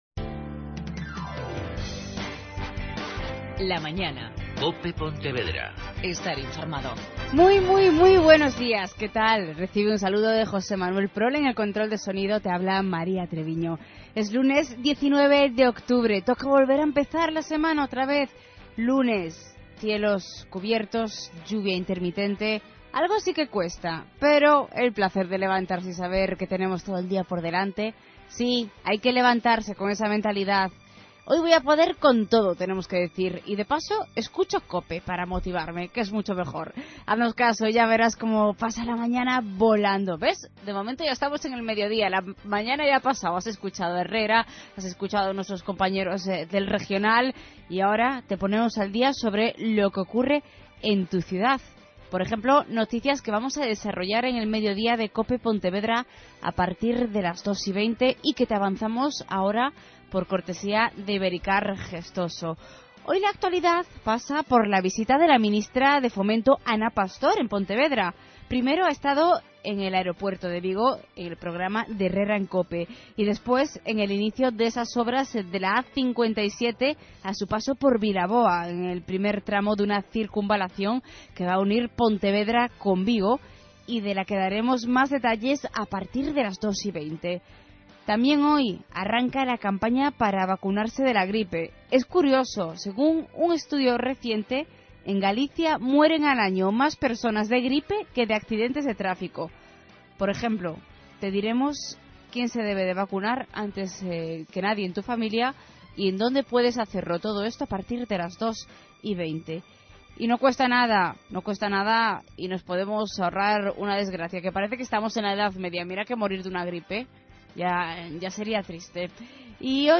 AUDIO: Entrevista al alcalde de A Illa, el señor Carlos Iglesias. Y nuestra sección de series de TV.